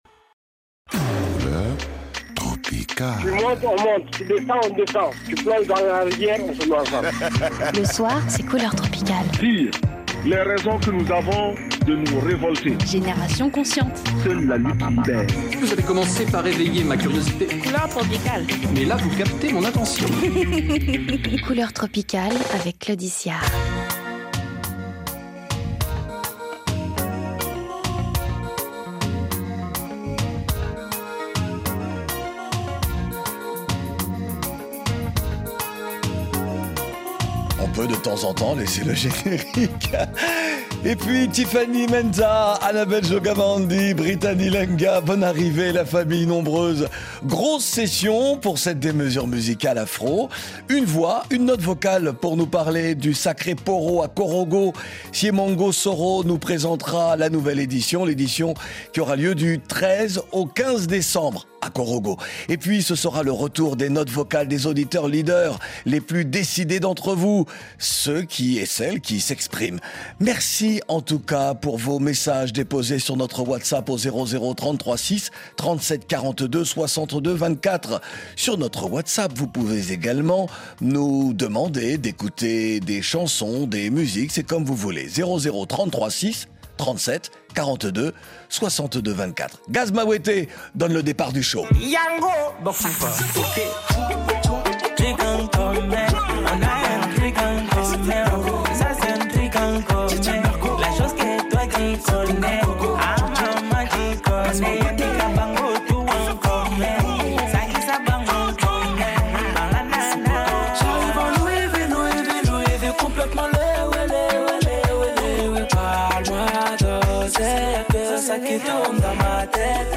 Programmation musicale consacrée aux nouveautés avec Gaz Mawete